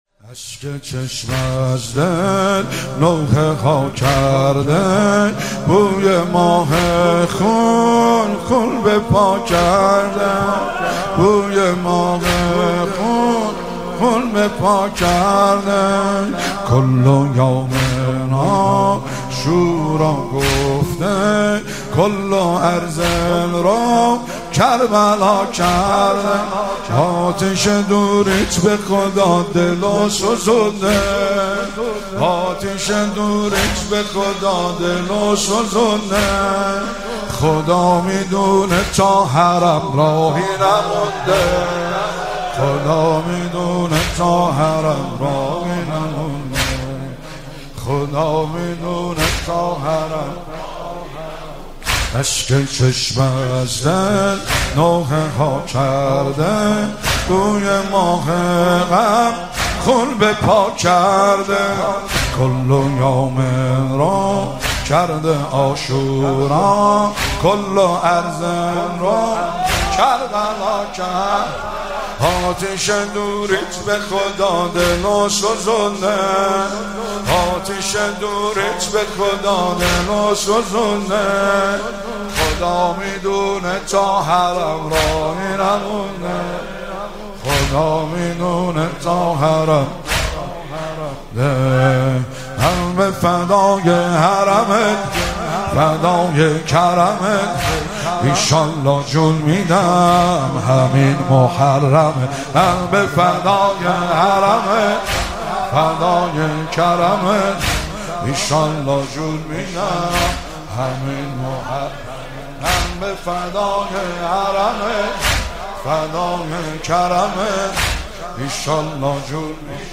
مدیحه سرایی
ذکر مصیبت اهل بیت (ع) و نیز مدیحه سرایی سوم ماه محرم
مداحی